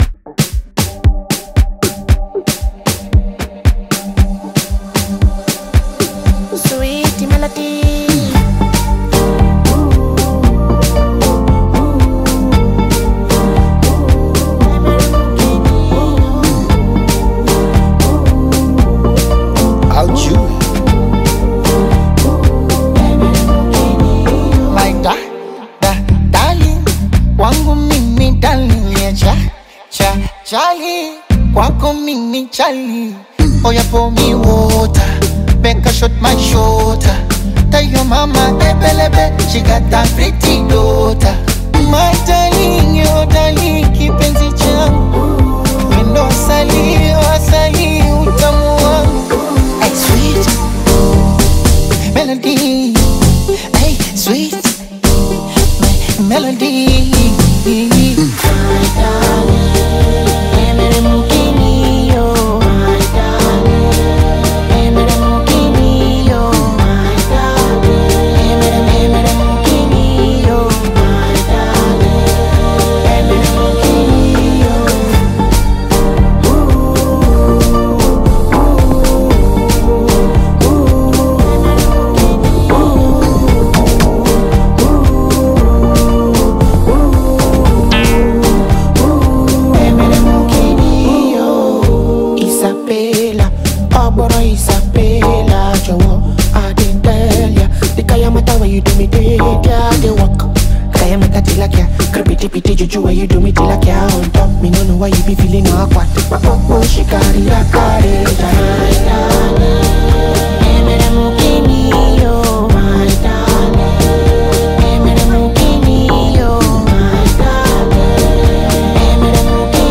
romantic Afrobeat/Bongo Flava single
Genre: Afrobeat